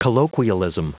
Prononciation du mot colloquialism en anglais (fichier audio)
Prononciation du mot : colloquialism